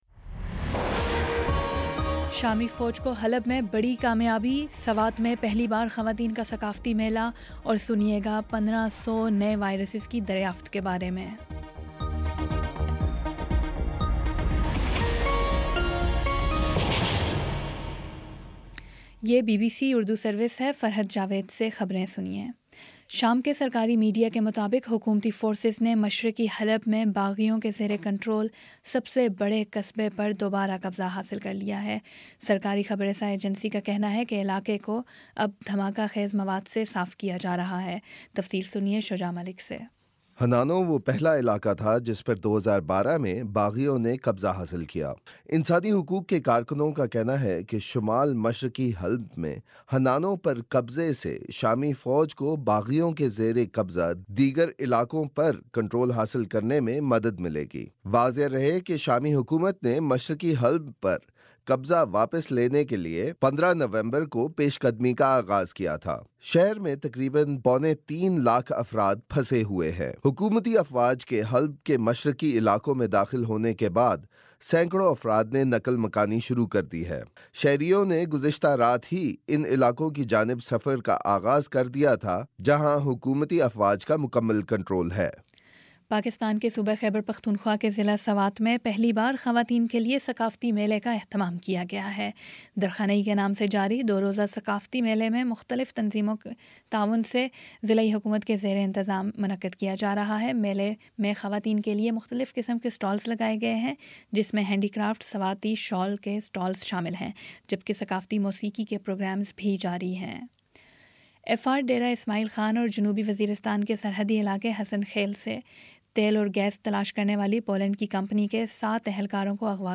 نومبر 27 : شام چھ بجے کا نیوز بُلیٹن